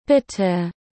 À l’inverse, en allemand, ce dernier se prononce, sans pour autant que l’on mette l’accent dessus : ne pas dire [biteu] mais bien [bi-te].
bitte.mp3